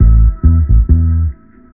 BIG125BASS-R.wav